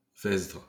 The Vesdre (French pronunciation: [vɛsdʁ]